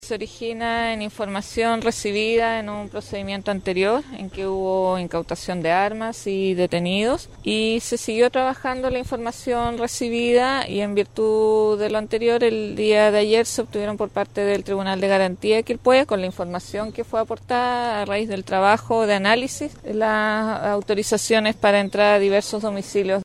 La fiscal adjunta jefe de Quilpué, Mónica Arancibia, se refirió al proceso de investigación, donde detalló que la información se obtuvo en un procedimiento anterior.